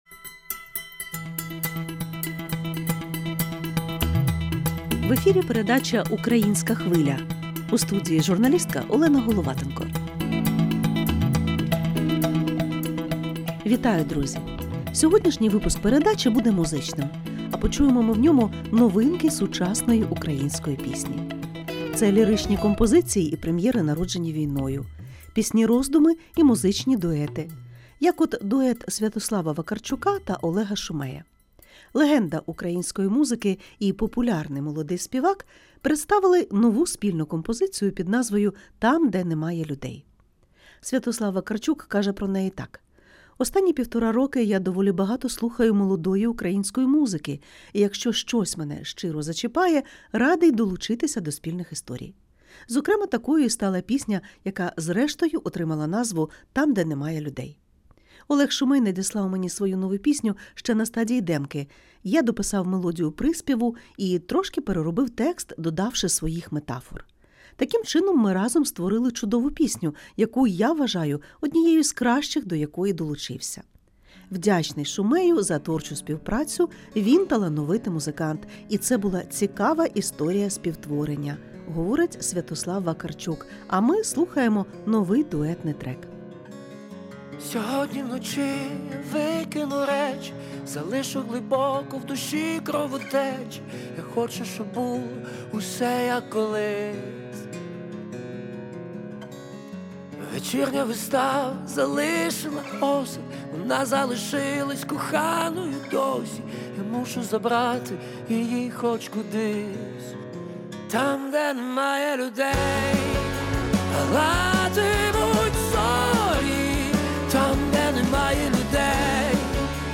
У музичному випуску передачі «Українська Хвиля» на радіо LRT Klasika знайомимося з новинками сучасної української пісні.
Це ліричні композиції і прем’єри, народжені війною, пісні-роздуми і музичні дуети.